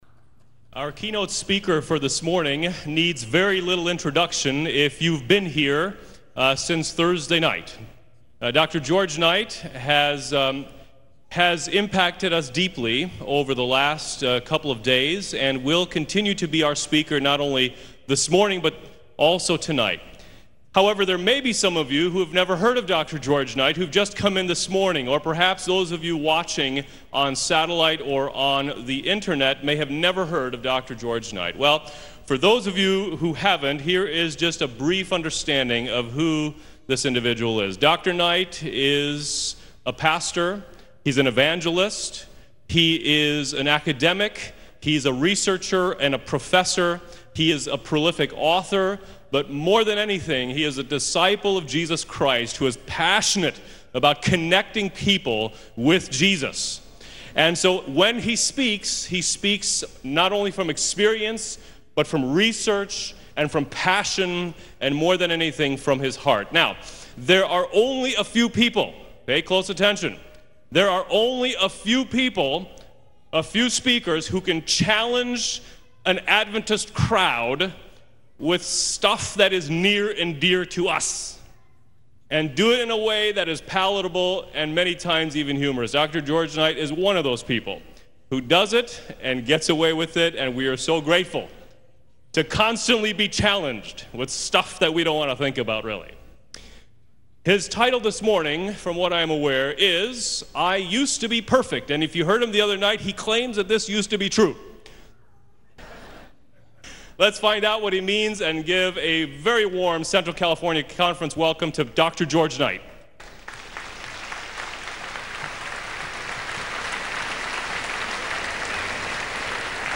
2016 Camp Meeting
on 2016-07-23 - Sabbath Sermons